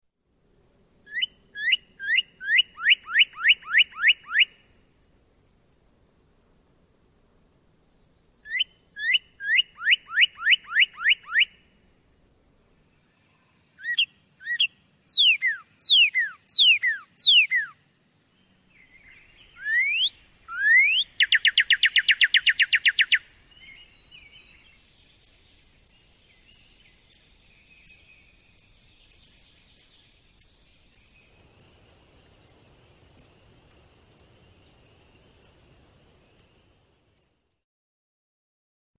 Bird song mp3s files are in the public domain.
northern-cardinal.mp3